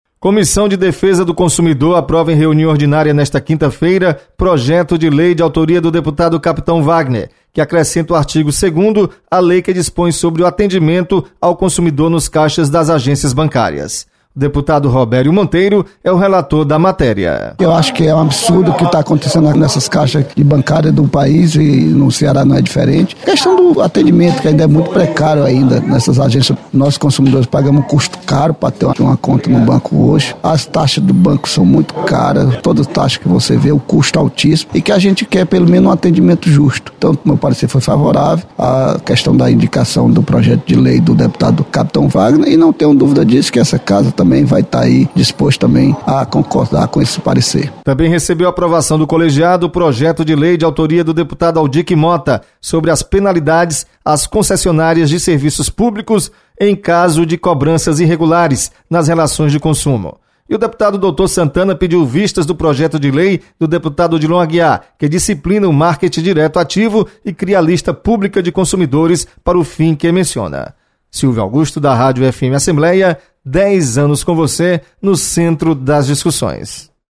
Comissão aprova lei sobre atendimento em caixas eletrônicos. Repórter